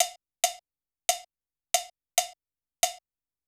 COW       -R.wav